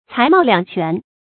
才貌两全 cái mào liǎng quán
才貌两全发音